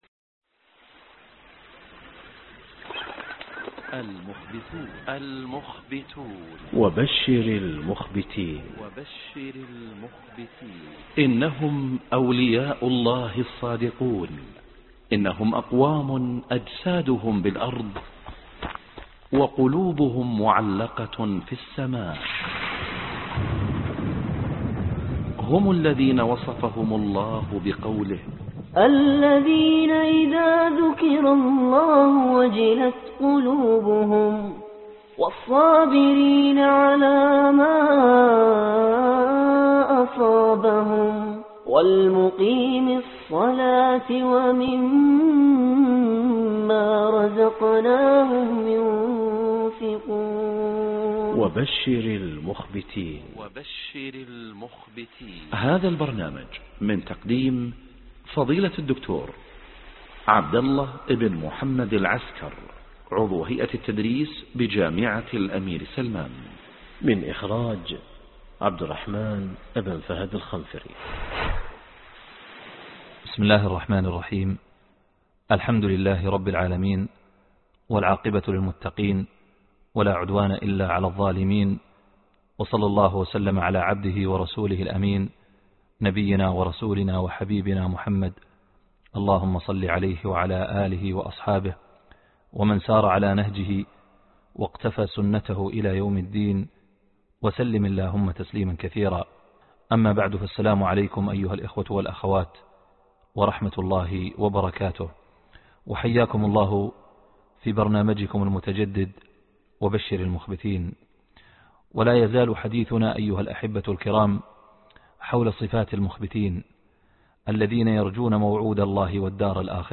الدرس 49 الصدق (وبشر المخبتين)